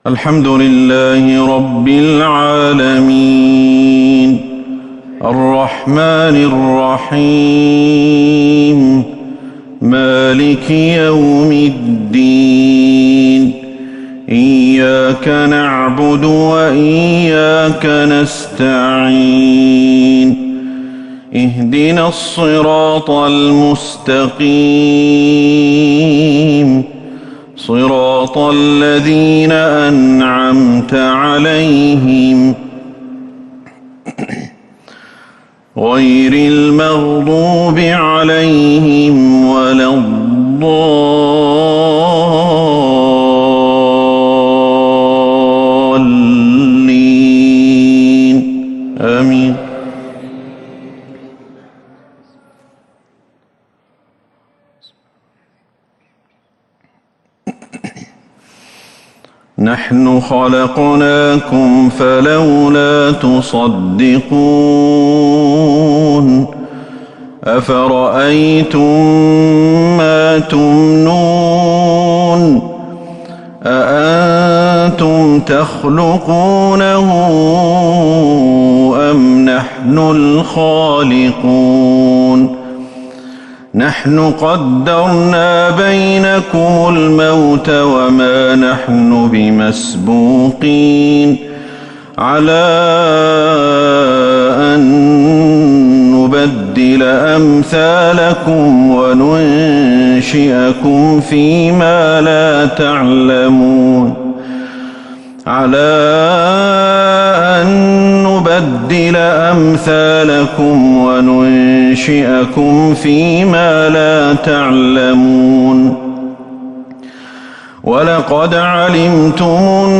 عشاء 20 جمادى الأولى 1442 هـ آواخر سورة {الواقعة} > 1442 هـ > الفروض - تلاوات الشيخ أحمد الحذيفي